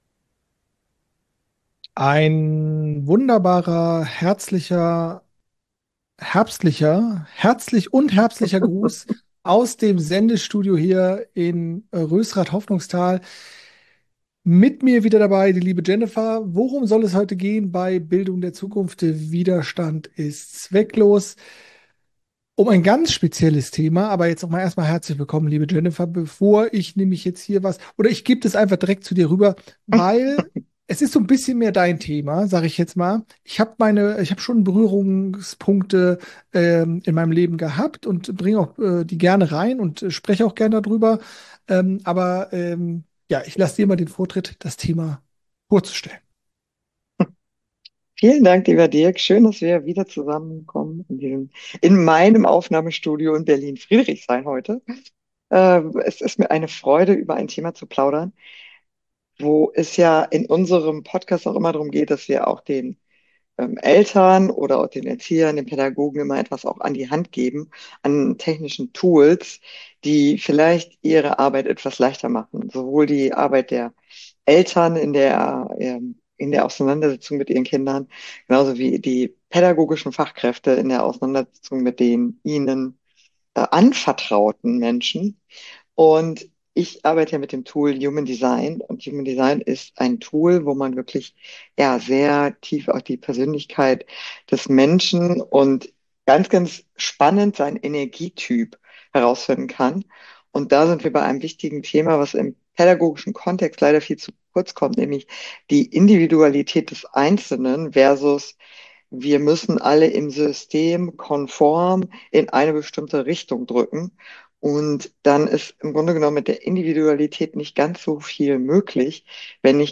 Human Design bietet eine solche Möglichkeit, die pädagogische Arbeit von Eltern und Fachkräften zu unterstützen. Wir geben heute in unserem Gespräch Einblicke in das Human Design und berichten von unseren praktischen Erfahrungen, die uns auf unserem Weg mit dem Wissen von Human Design zu mehr innerem Frieden und Verständnis für uns selber geführt haben.